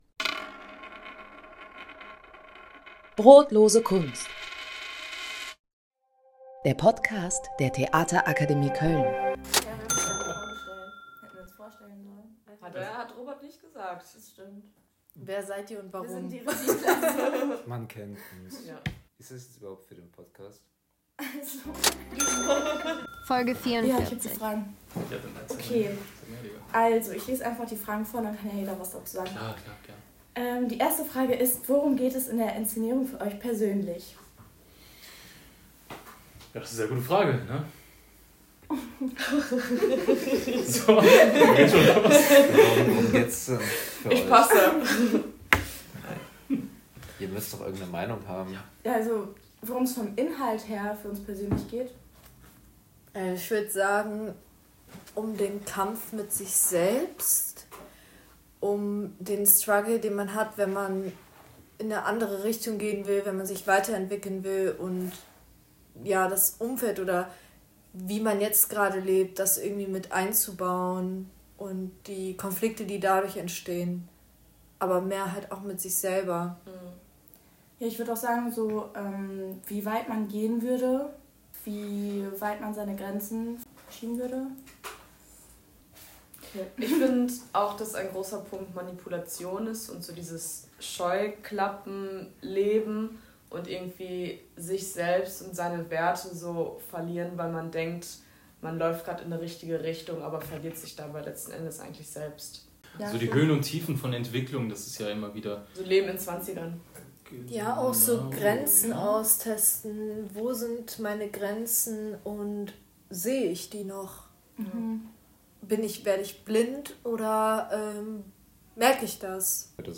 In dieser Folge haben wir der Regieklasse ein paar Fragen in die Hand gedrückt und sie gebeten, die Antworten aufzunehmen.